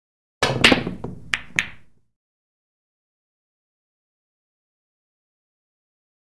На этой странице собраны звуки бильярда: от четких ударов кием до глухого стука шаров и их падения в лузу.
Звук классического удара кия по бильярдному шару